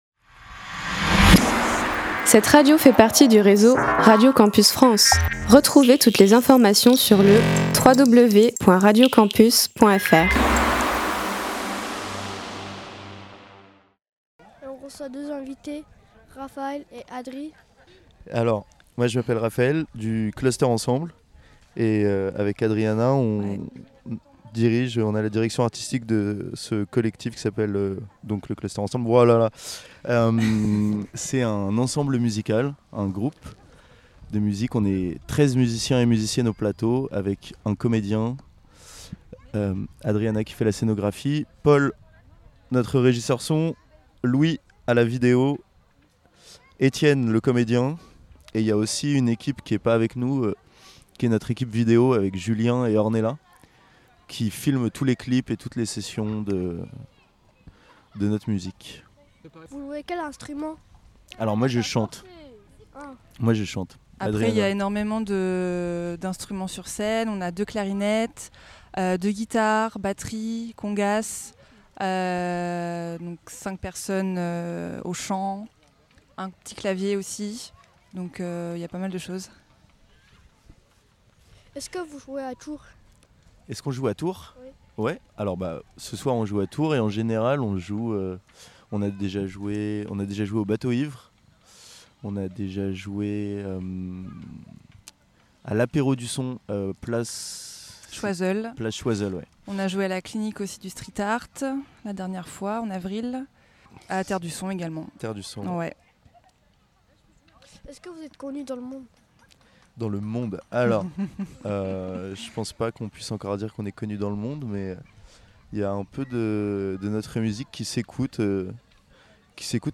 On a pu se promener avec nos micros sur le festival et réaliser quelques interviews que nous vous proposons ici en podcasts.
itw_clusterensemble.mp3